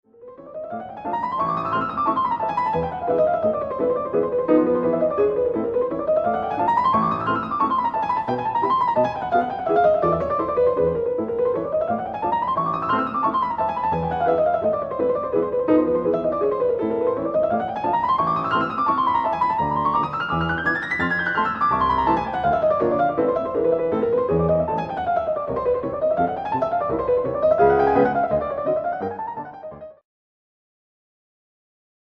Música del Romántico I